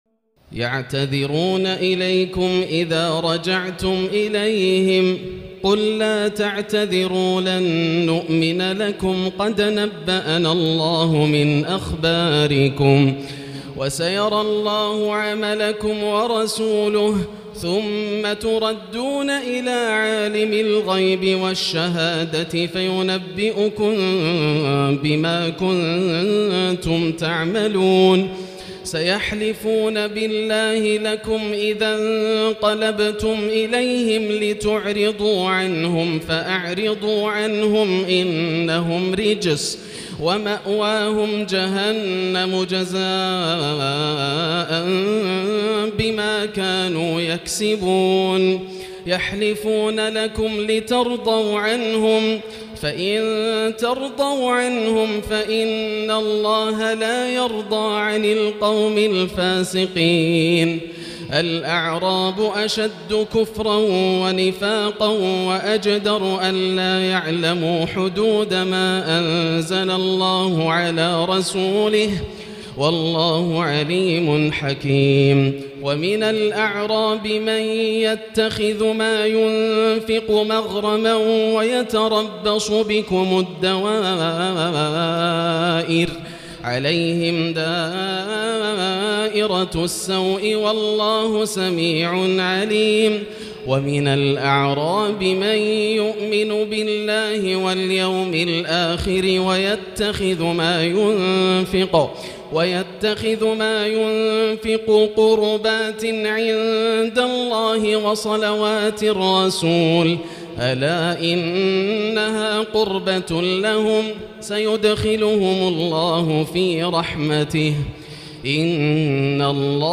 الليلة العاشرة تلاوة من سورتي التوبة 94-129و يونس1-25 > الليالي الكاملة > رمضان 1439هـ > التراويح - تلاوات ياسر الدوسري